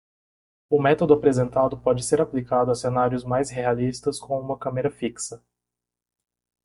Pronounced as (IPA) /a.pliˈka.du/